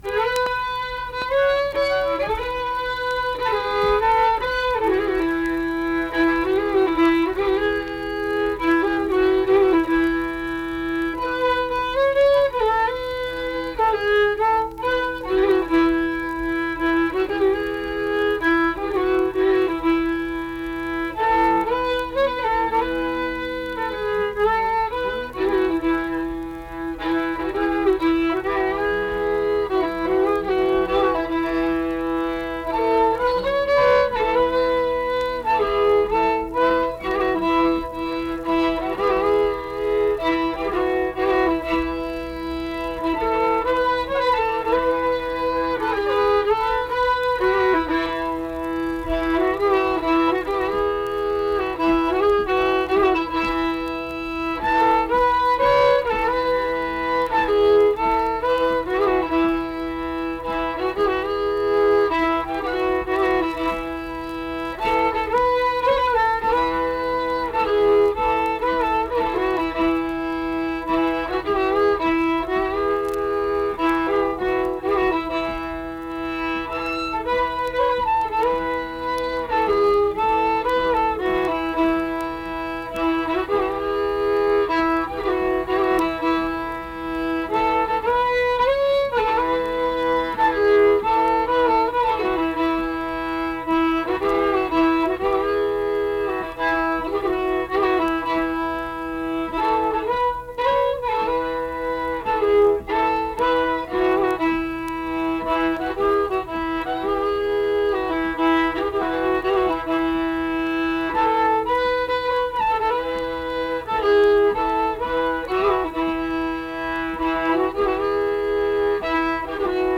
Accompanied guitar and unaccompanied fiddle music performance
Guitar accompaniment
Instrumental Music
Fiddle